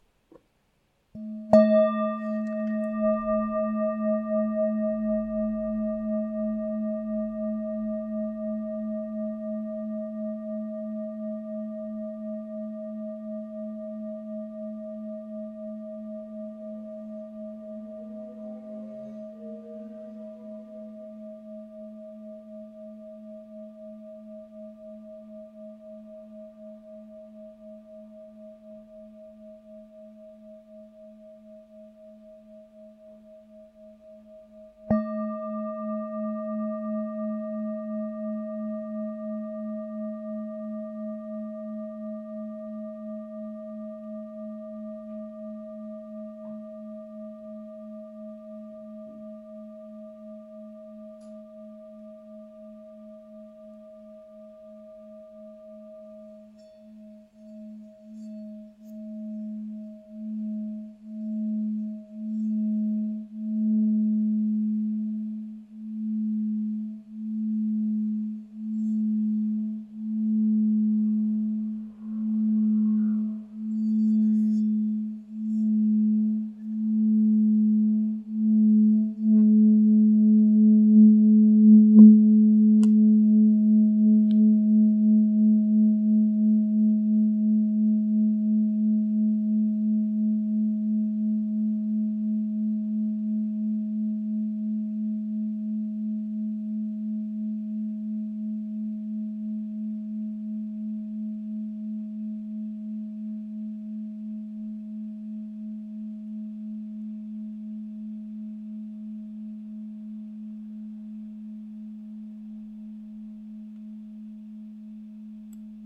Campana Tibetana in lega dei 7 metalli Nota LA(A) 3 218 HZ – R201/NI ideale per yoga e meditazione
Nota Armonica RE(D) #5 632 HZ
Nota di fondo LA(A) 3 218 HZ